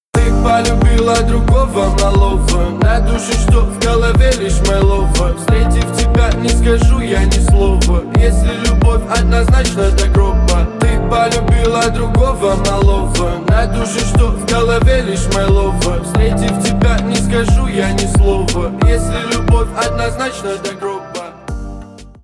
Рэп и Хип Хоп
клубные